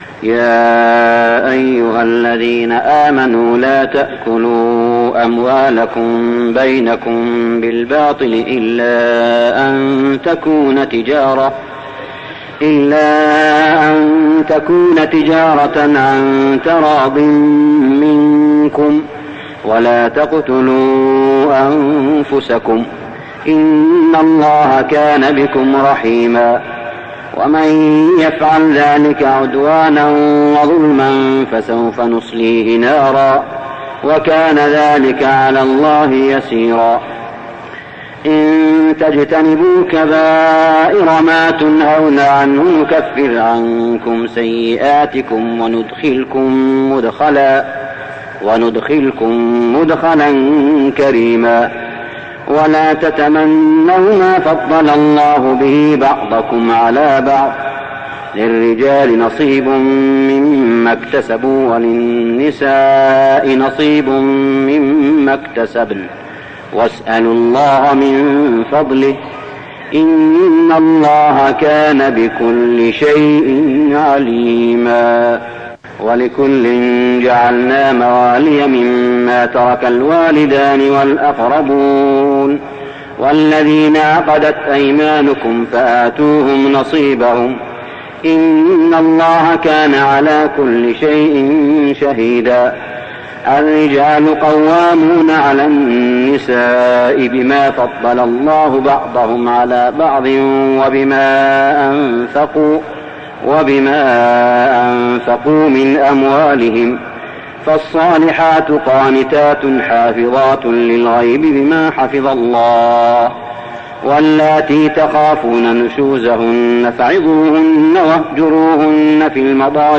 صلاة التراويح ليلة 5-9-1409هـ سورة النساء 29-87 | Tarawih prayer Surah An-Nisa > تراويح الحرم المكي عام 1409 🕋 > التراويح - تلاوات الحرمين